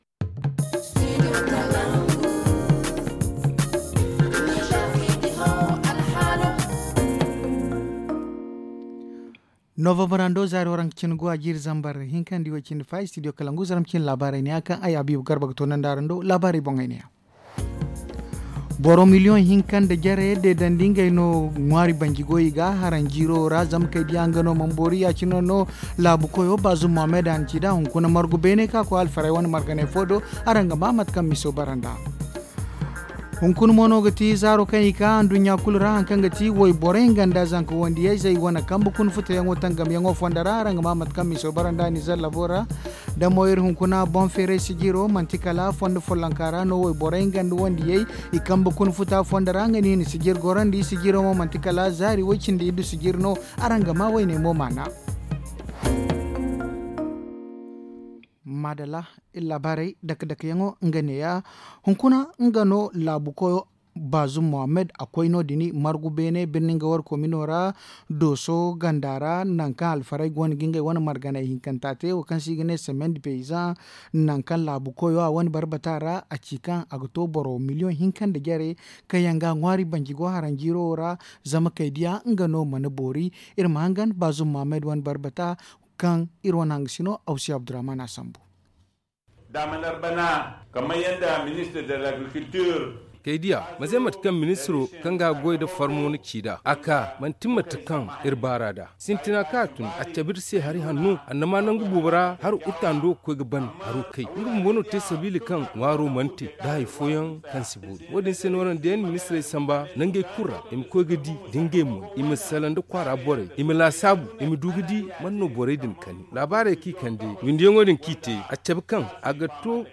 Le journal du 25 novembre 2021 - Studio Kalangou - Au rythme du Niger